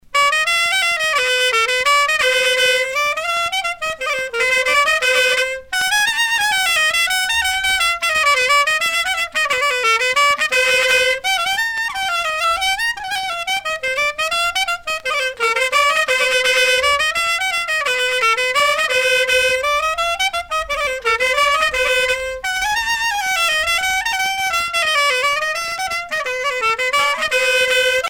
danse : fisel (bretagne)
Pièce musicale éditée